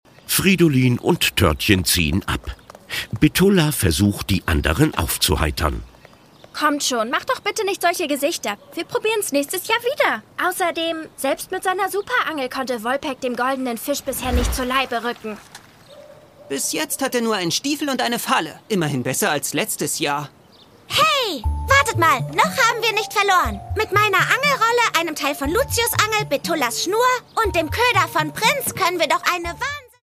Das Original-Hörspiel zur TV-Serie
Produkttyp: Hörspiel-Download